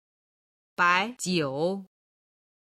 中国語音源 随意 (suí yì) 自由に。